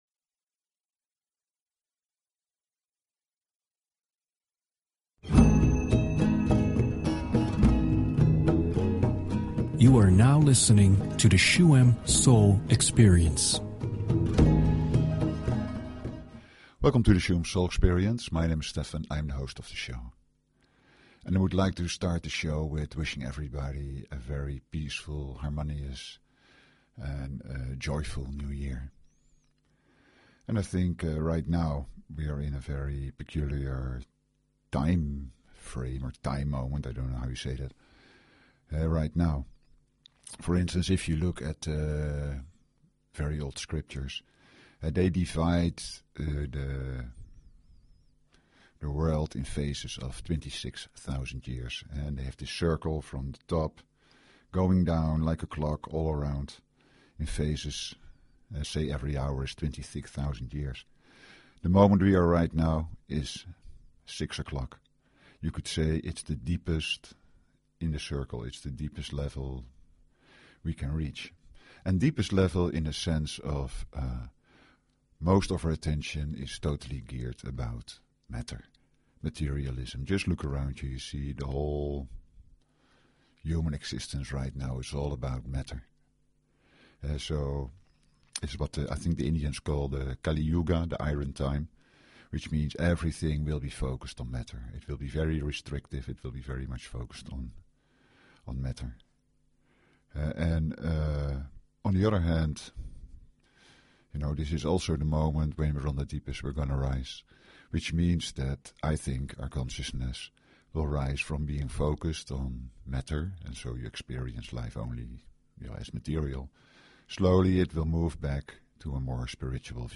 Talk Show Episode, Audio Podcast, Shuem_Soul_Experience and Courtesy of BBS Radio on , show guests , about , categorized as
To experience the ritual optimally it is advised to listen to it through headphones, sit or lie down and relax.